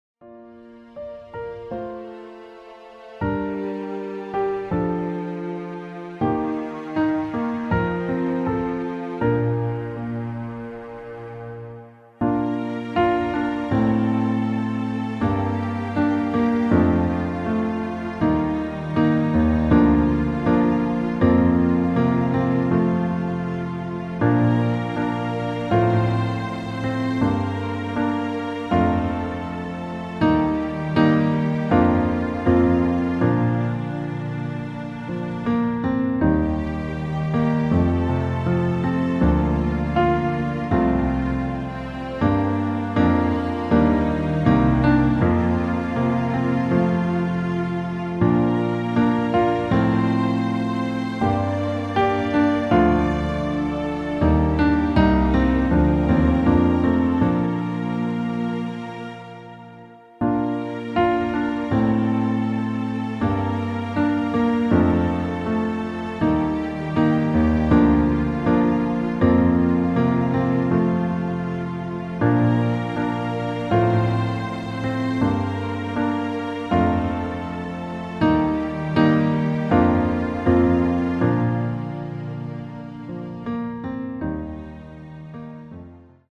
Art der Einspielung Klavier / Streicher